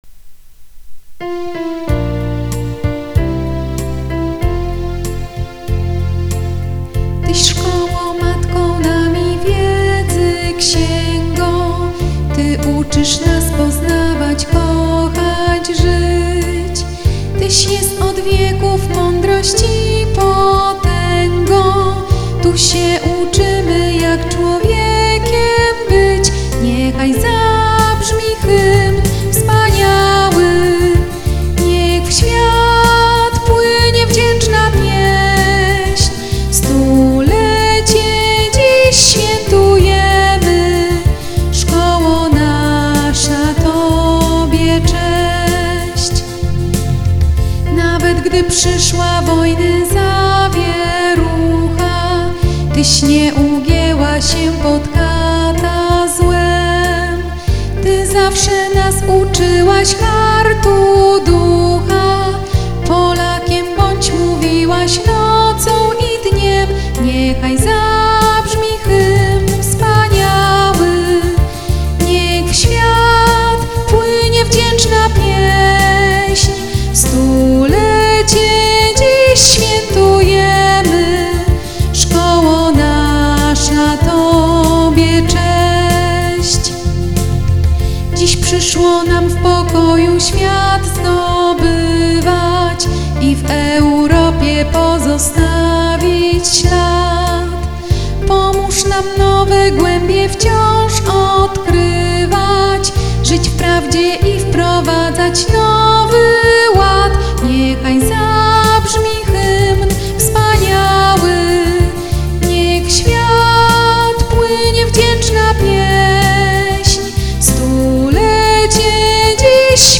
Nagranie Orkiestry Reprezentacyjnej Policji w Warszawie
hymn_z_vocalem__online_audio_converter.com_.mp3